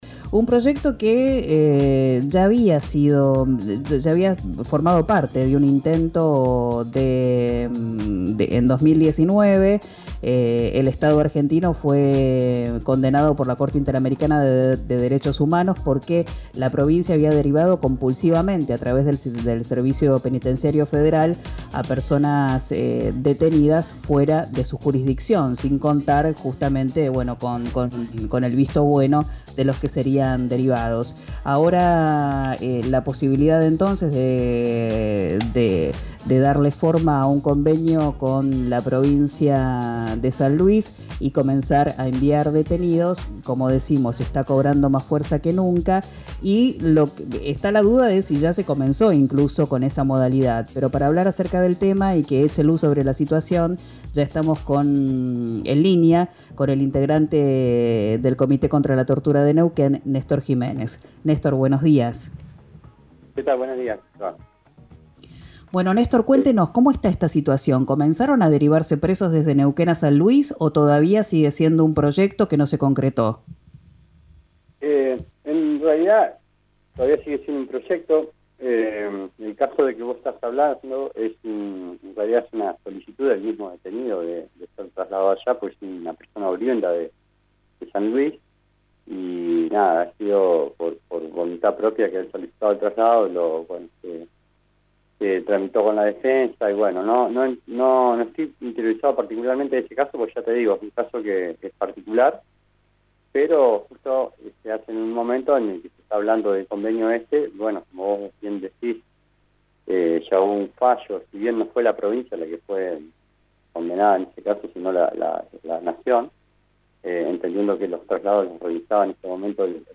Escuchá la entrevista completa en RÍO NEGRO RADIO.